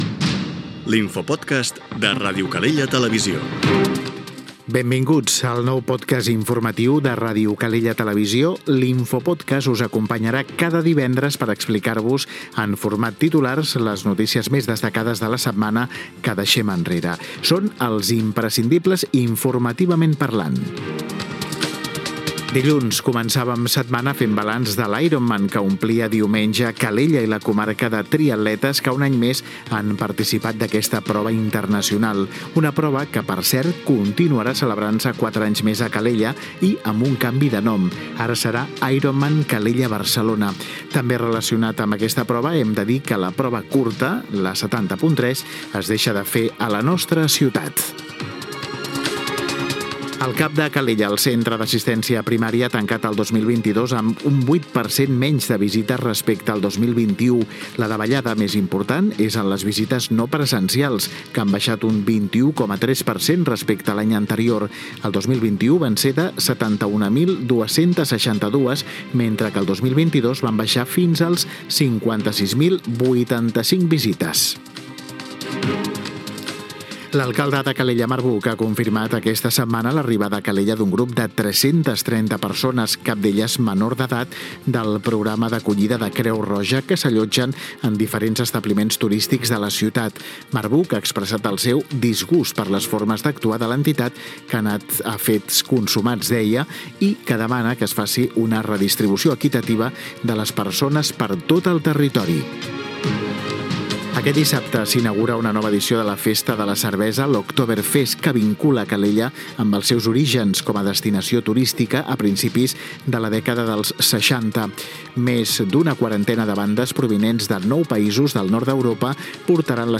Indicatiu del programa, presentació, la competició Iron man, les visites al CAP de Calella, October Fest, increment dels impostos i indicatiu
Informatiu